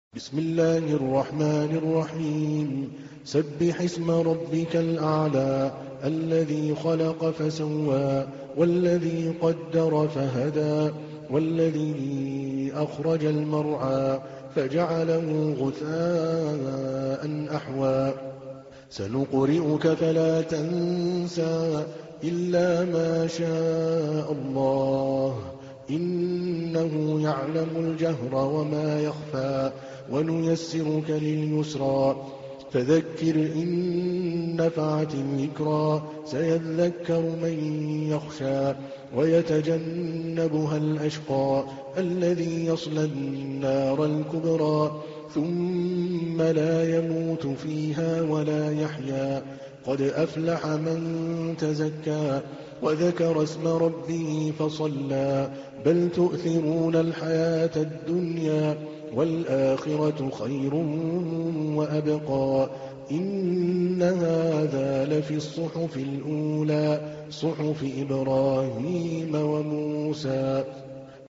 تحميل : 87. سورة الأعلى / القارئ عادل الكلباني / القرآن الكريم / موقع يا حسين